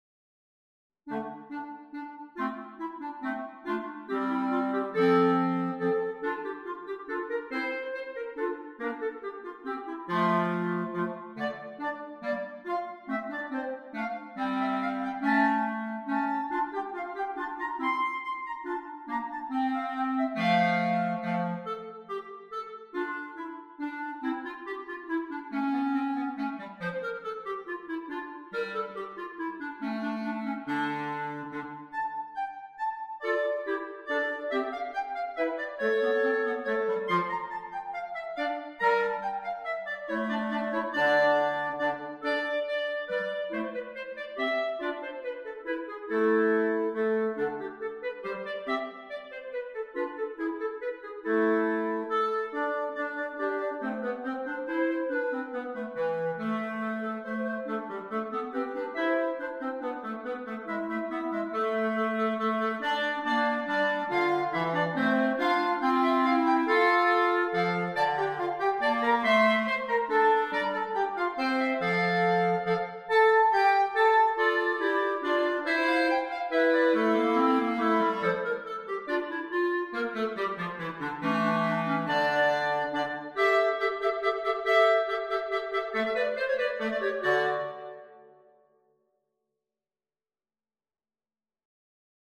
Arranged for clarinet trio.